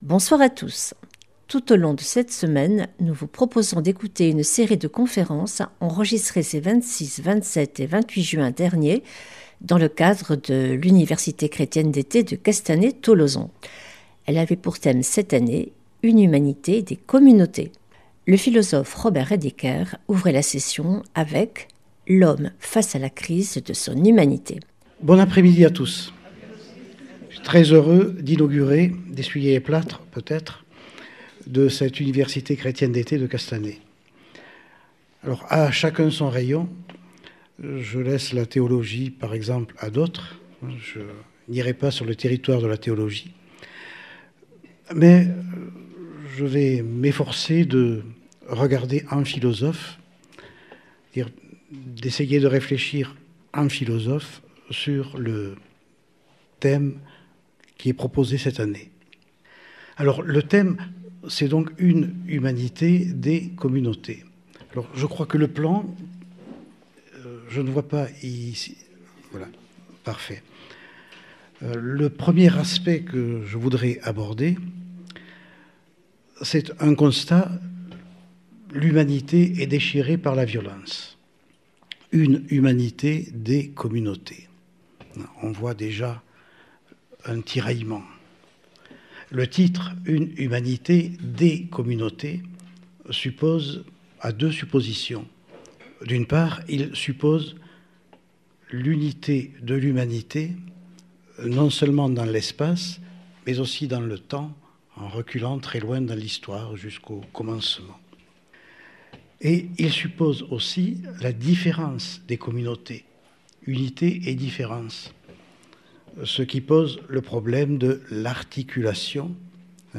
Conférence de la semaine du 16 sept.
Robert Redecker, philosophe L'homme face à la crise de son humanité